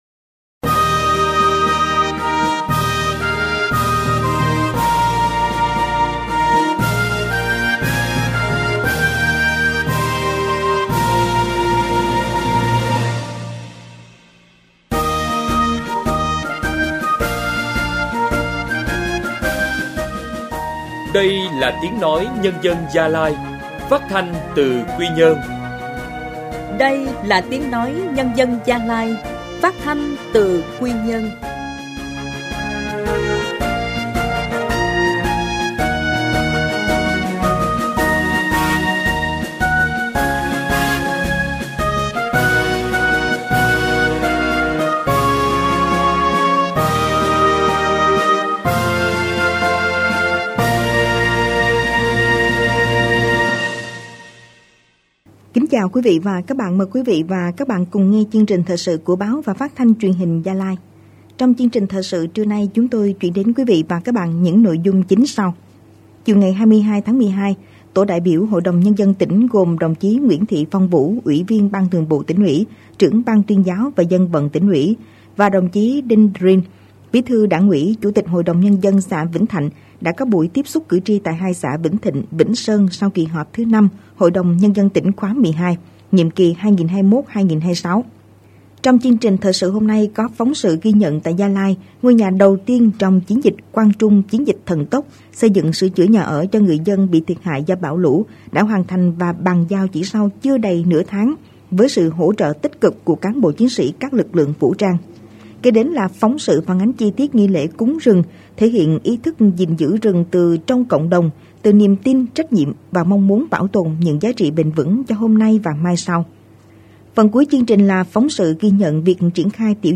Thời sự phát thanh trưa